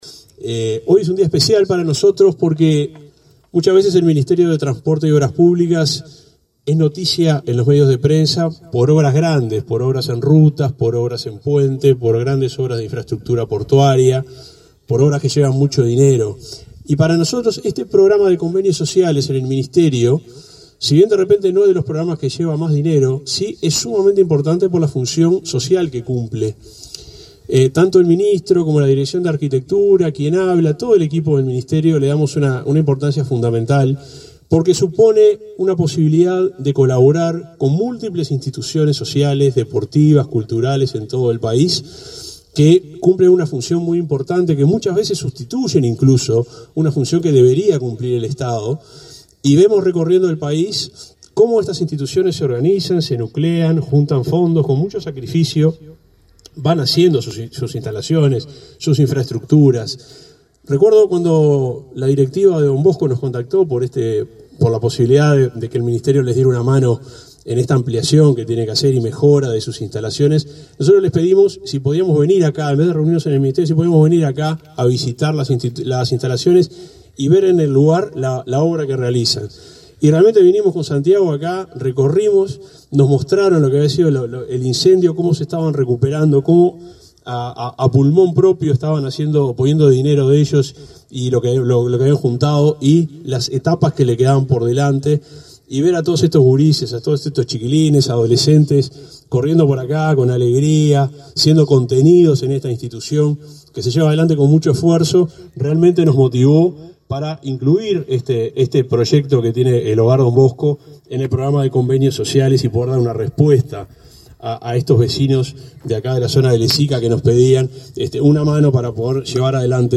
Palabras del subsecretario de Transporte